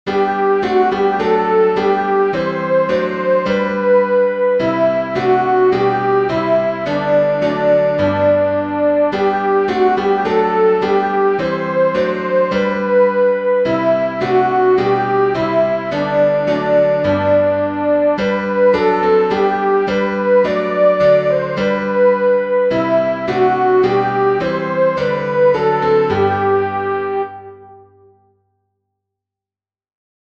Soprano
dix_as_with_gladness_men_of_old-soprano.mp3